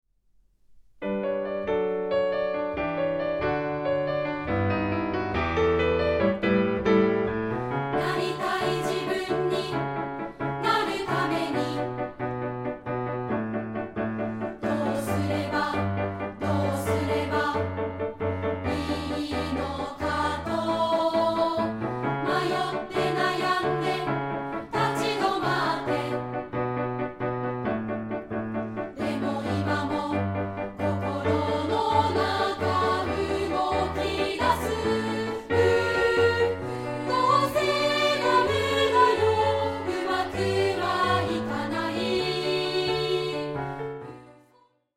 2部合唱／伴奏：ピアノ